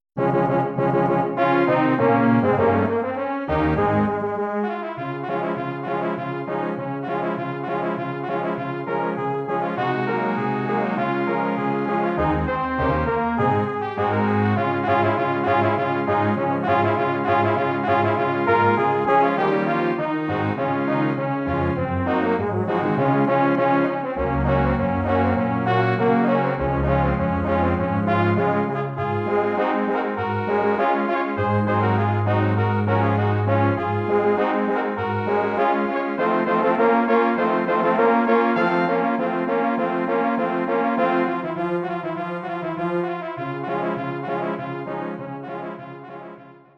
Bearbeitung für Hornoktett
Besetzung: 8 Hörner
arrangement for horn octet
Instrumentation: 8 French horns